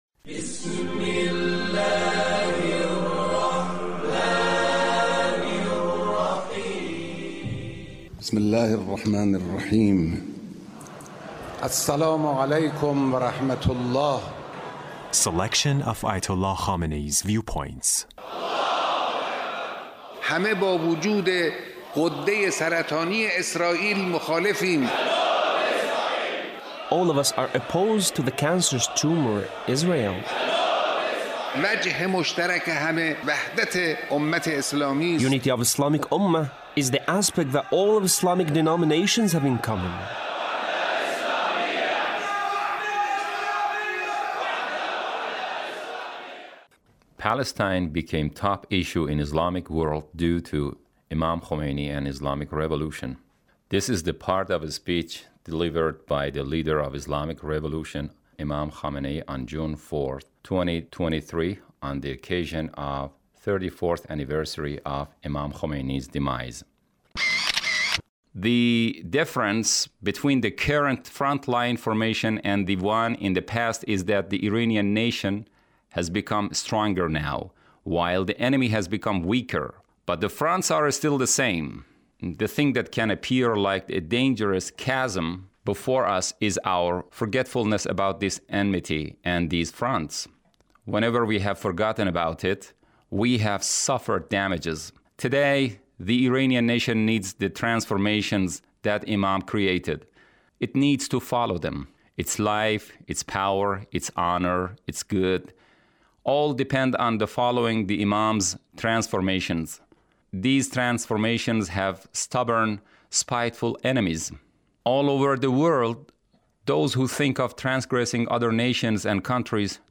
Leader's Speech (1741)
Leader's Speech on the occasion of the 34th ََAnniversary of Imam Khomeini’s Demise. 2023